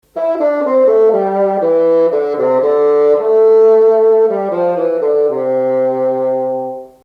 Instrumente | Fagott – Musikschule Region Baden
fagott.mp3